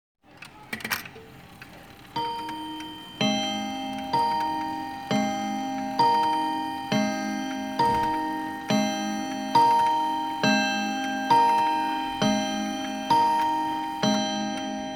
Luxusní mechanické nástěnné hodiny
Půlové bití - v půl odbijí 1x, v celou počet hodin
Půlové bití dvoutónové
2055-pulove-biti-mechanicke-dvoutonove.mp3